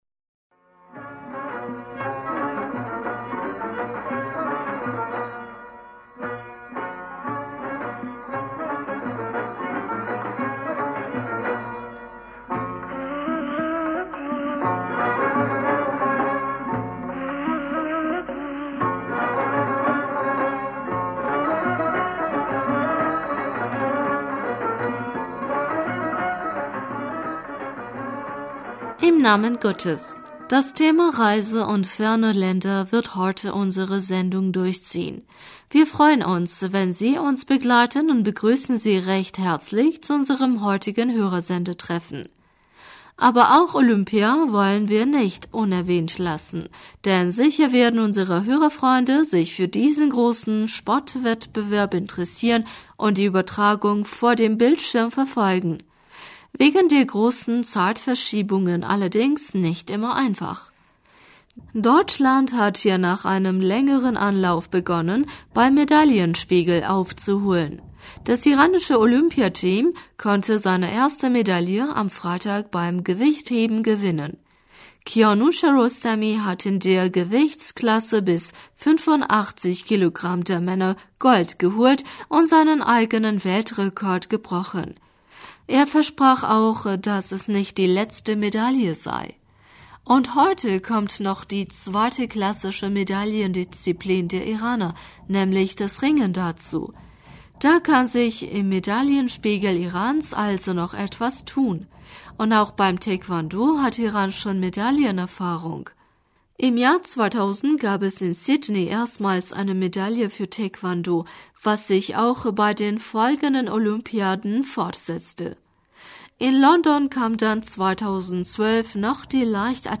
Hörerpostsendung